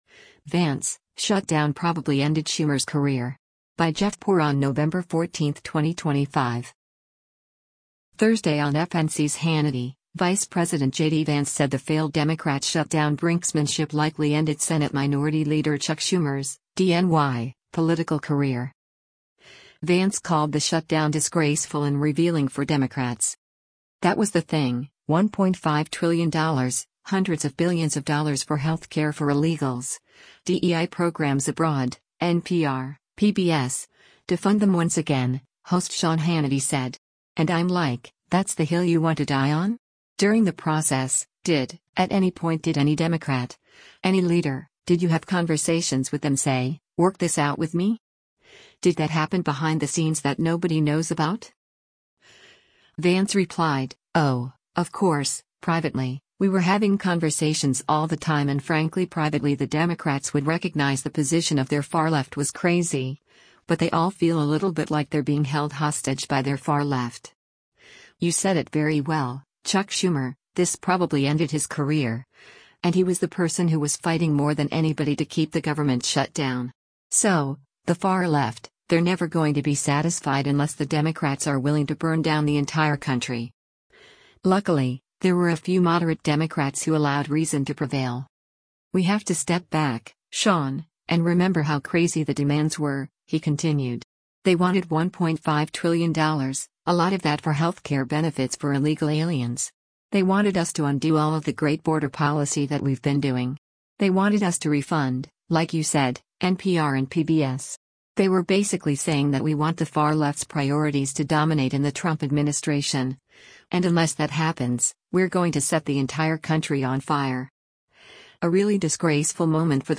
Thursday on FNC’s “Hannity,” Vice President JD Vance said the failed Democrat shutdown brinksmanship likely ended Senate Minority Leader Chuck Schumer’s (D-NY) political career.